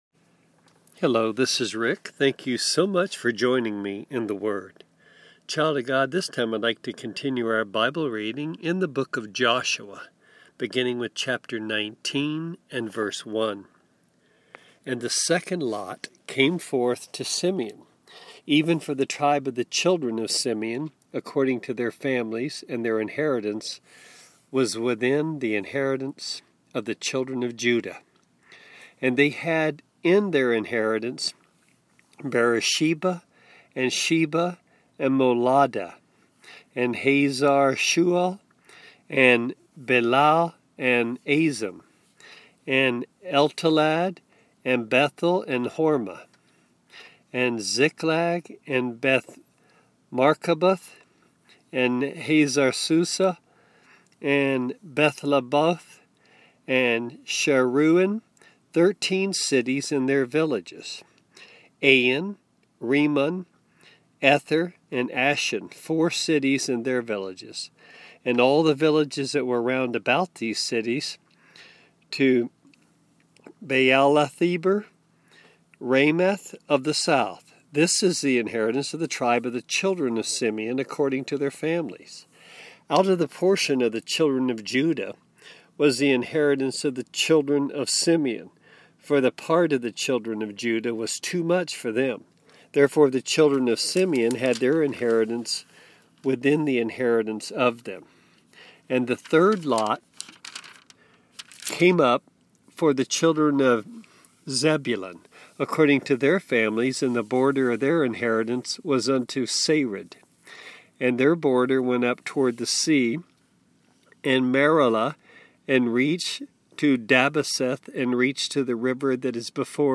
Bible Reading & Commentary